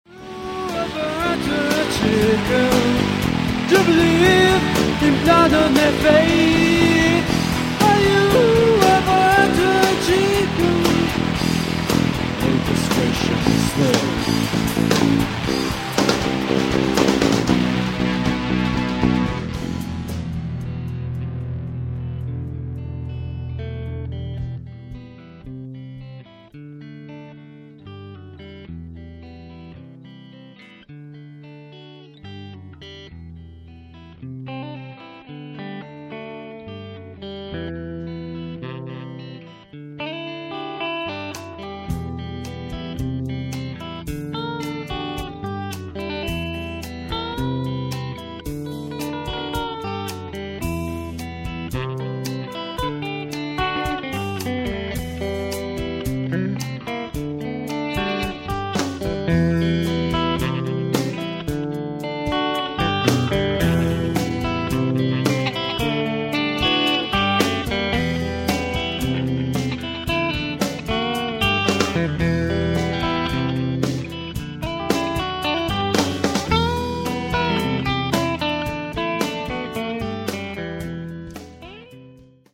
voce, chitarra elettrica
batteria
chitarra acustica
basso